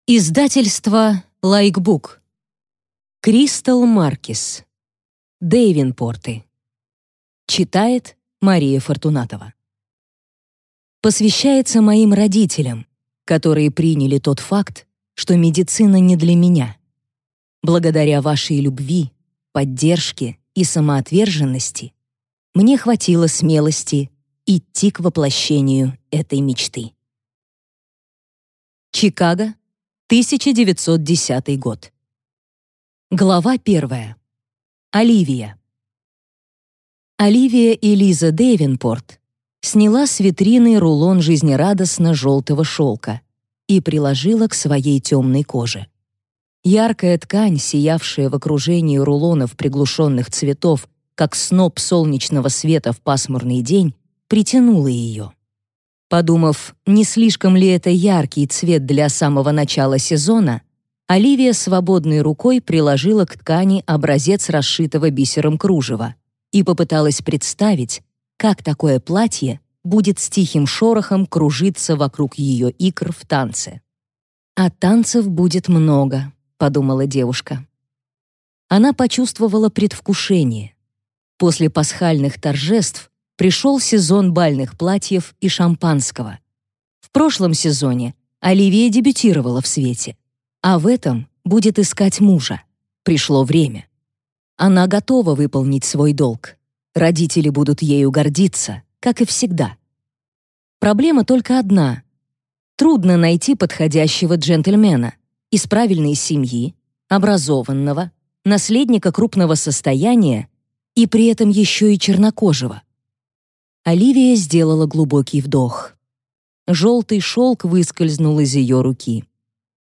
Аудиокнига Дэйвенпорты | Библиотека аудиокниг